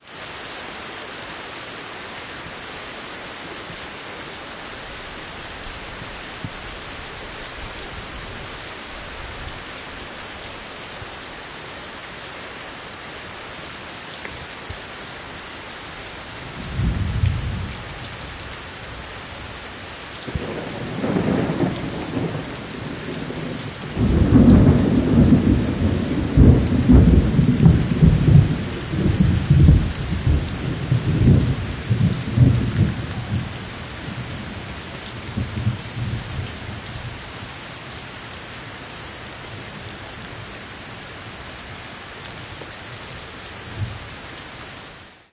雨 雷
描述：记录在泰国清迈的夜晚（2015年初）。有一些非常小的不必要的噪音（如雨水落在麦克风上）。
Tag: 国家 泰国 清迈 风暴